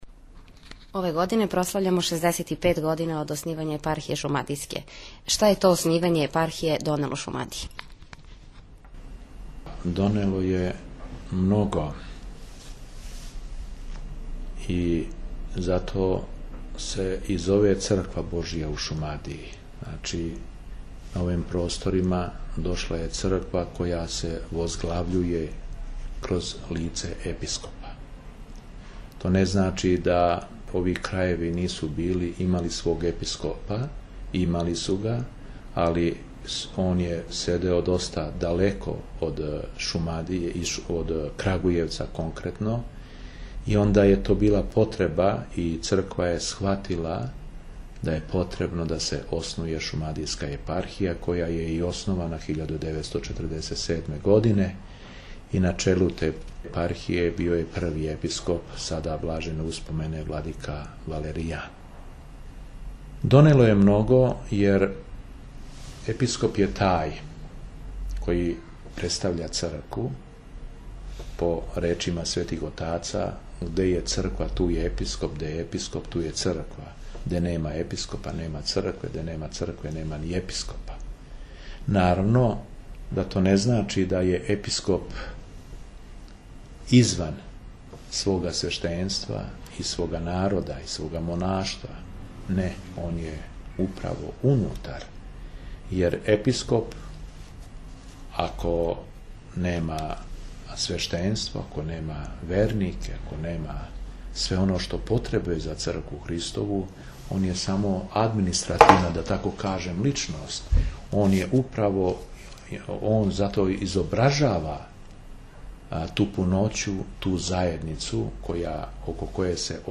Интервју са епископом шумадијским Г. Јованом